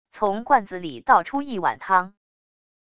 从罐子里倒出一碗汤wav音频